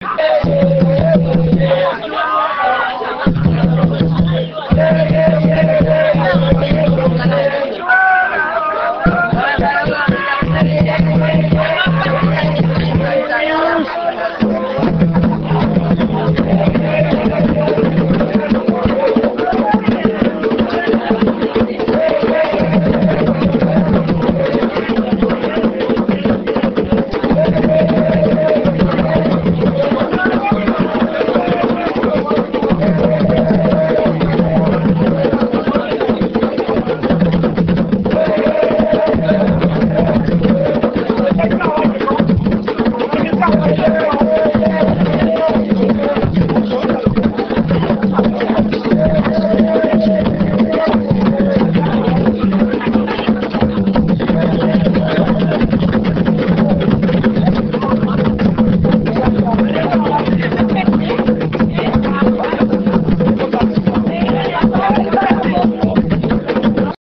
enregistrement durant une levée de deuil (Puubaaka)
danse : awassa (aluku)
Genre songe
Pièce musicale inédite